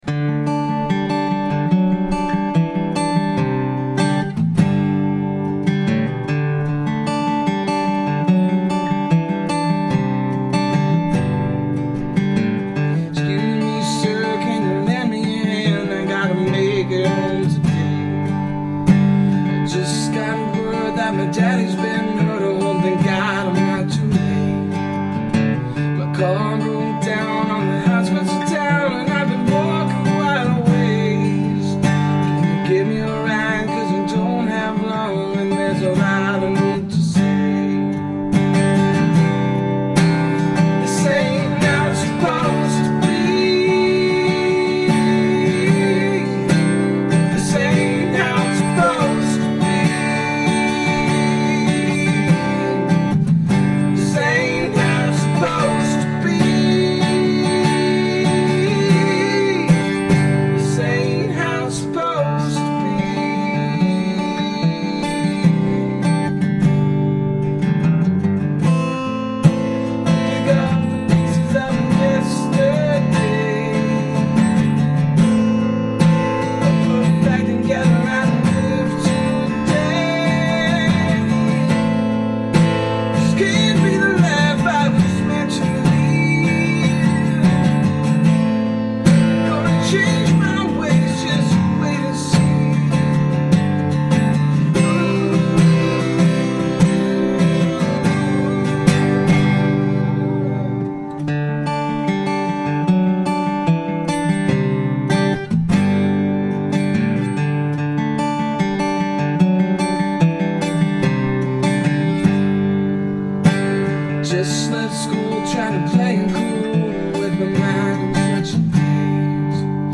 Worktape